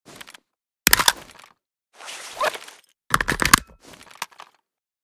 usas12_reload.ogg.bak